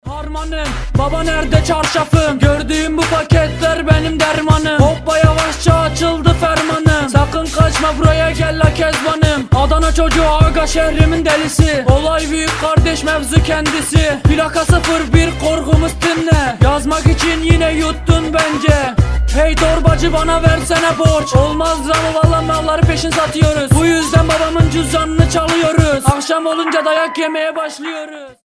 Хип-хоп
веселые
Инопланетянин флексит под турецкий рэп - мем